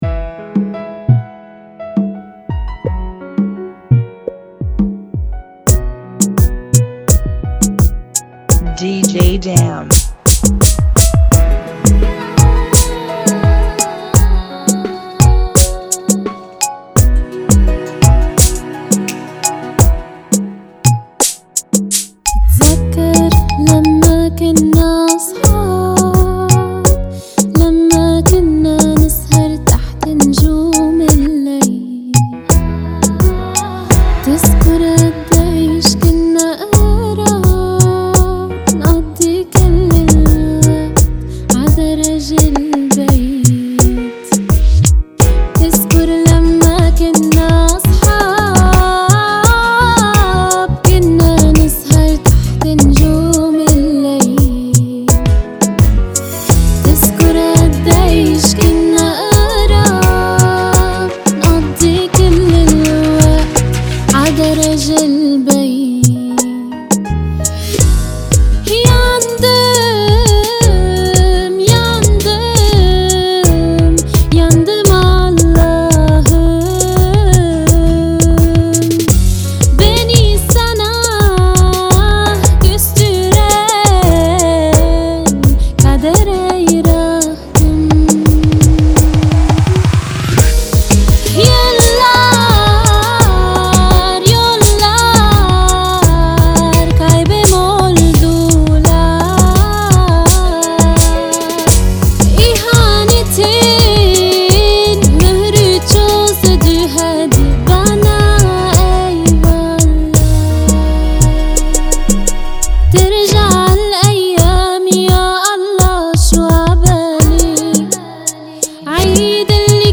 (85 BPM)
Genre: Kizomba Remix